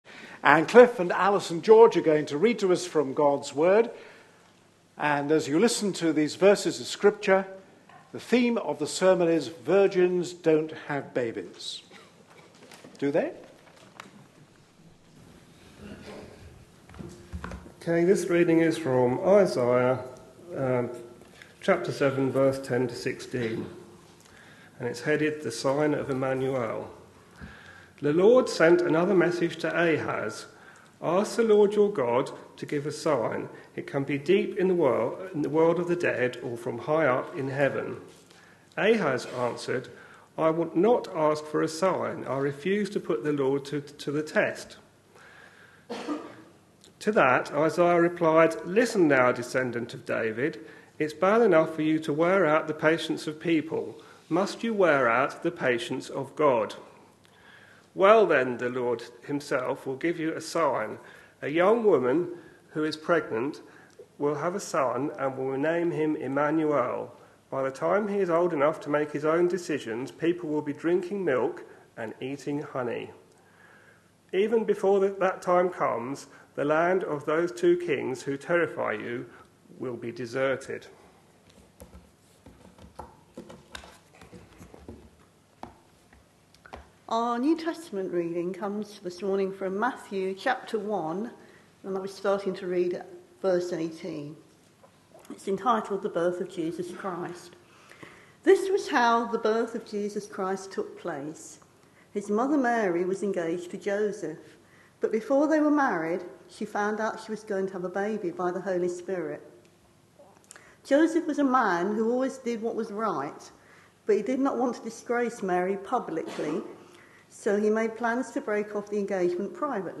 A sermon preached on 15th December, 2013, as part of our Objections to faith answered! series.